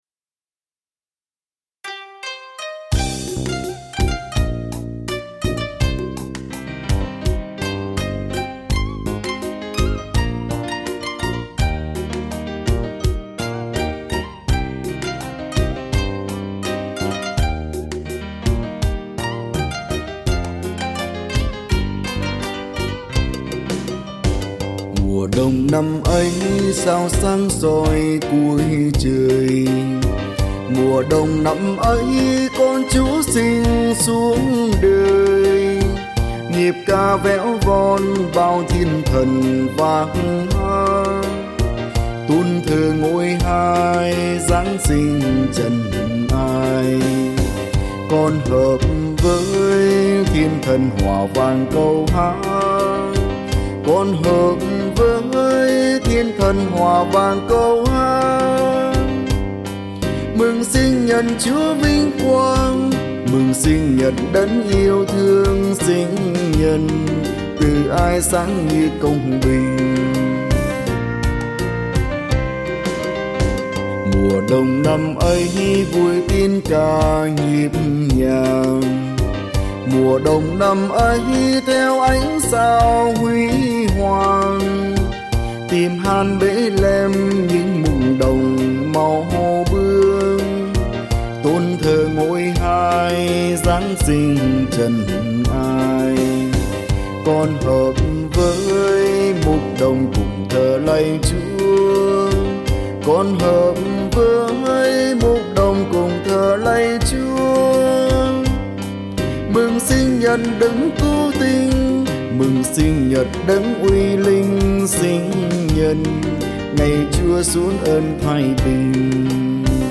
Thể loại: Giáng Sinh